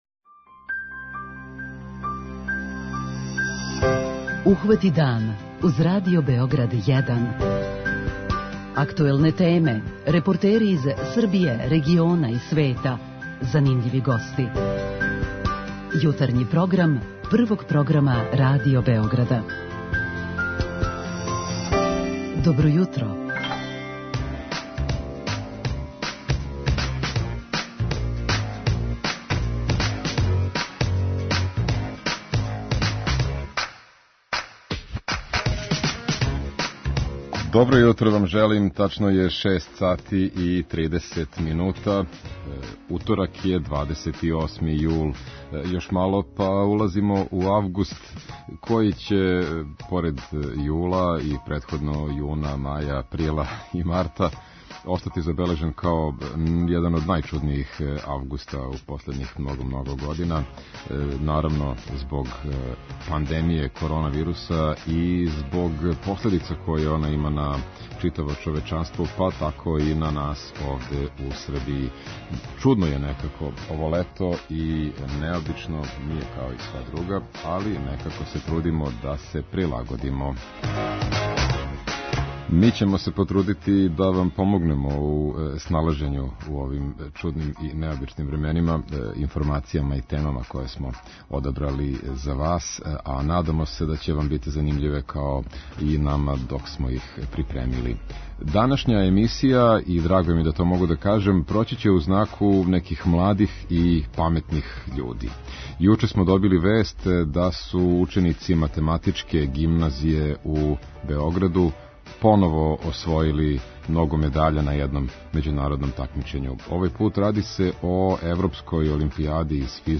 У редовној рубрици "Питање јутра" питаћемо слушаоце шта мисле о иницијативи за промену назива улица у Београду и најављеном укидању улица које носе називе топонима из некадашње СФРЈ.
преузми : 32.20 MB Ухвати дан Autor: Група аутора Јутарњи програм Радио Београда 1!